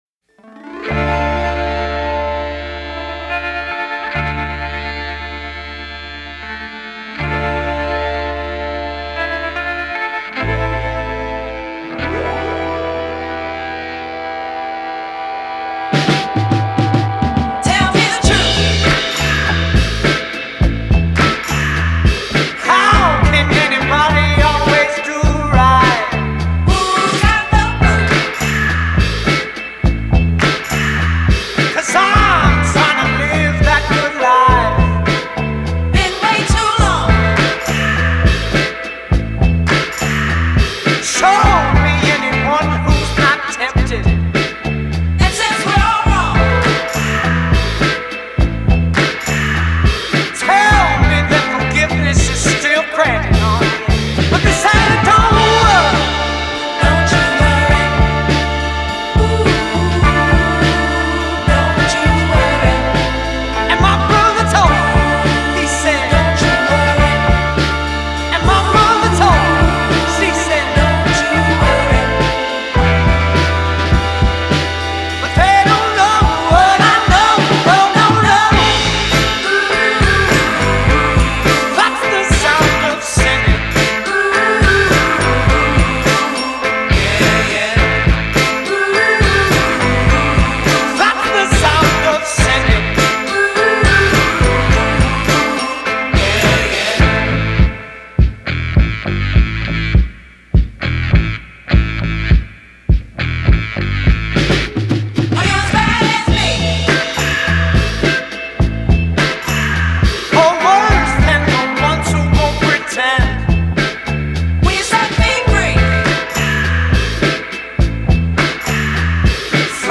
Style: Funk, Soul, Psychedelic